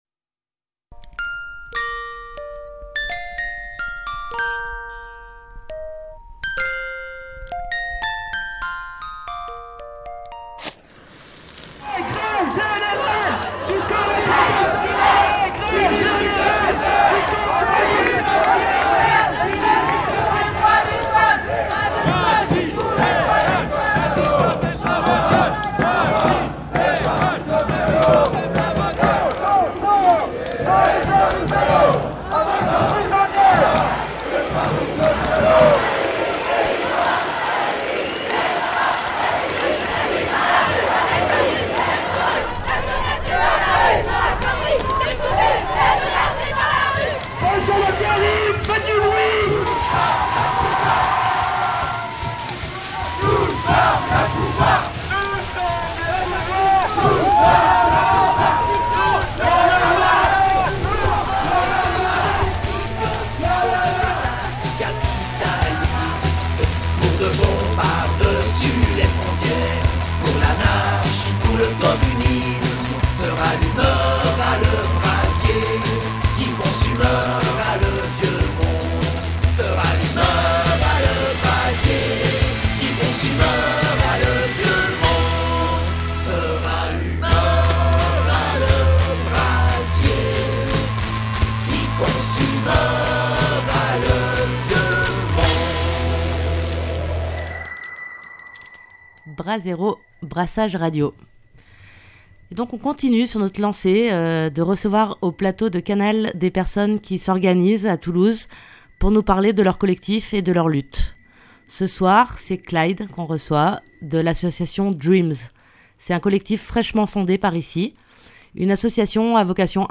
On continue de recevoir au plateau de Canal des personnes qui s’organisent à Toulouse, pour nous parler de leur collectifs et de leurs luttes.